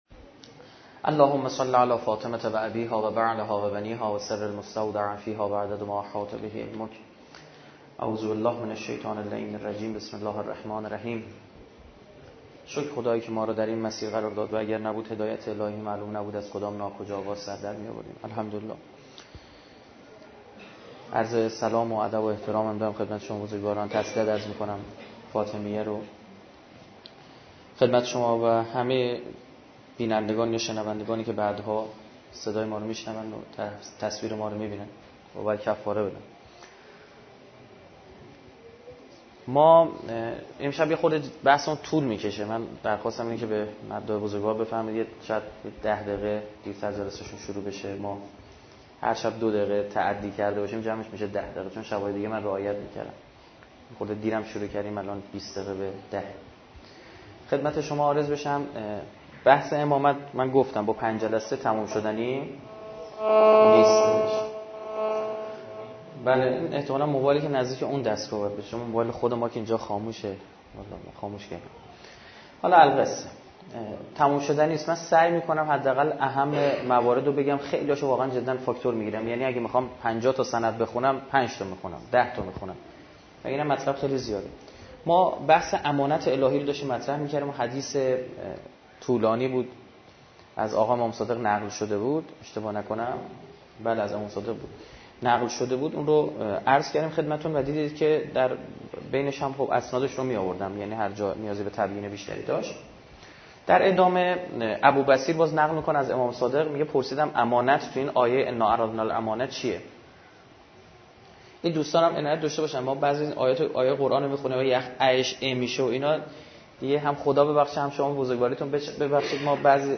سخنرانی
در حسینه لباس فروشان ایام فاطمیه